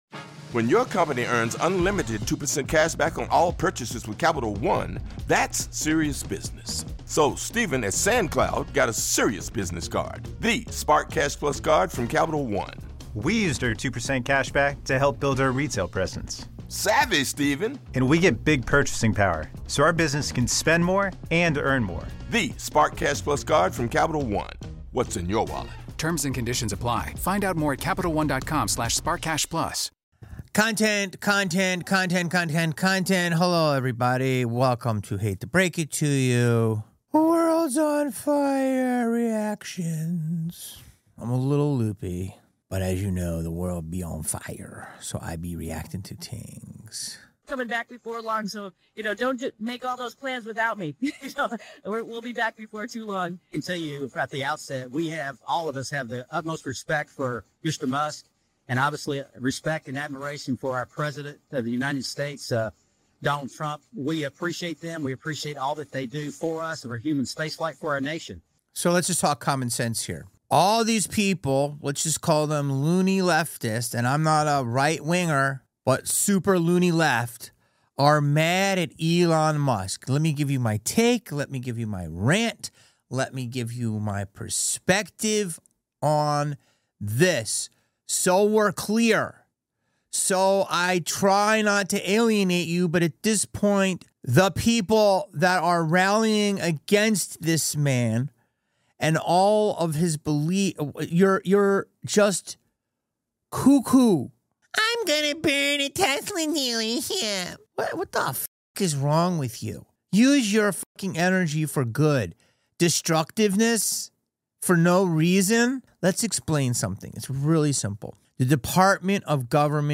HATE TO BREAK IT TO YA is a weekly podcast hosted by actor/comedian Jamie Kennedy. Jamie is on a constant quest to get to the bottom of things.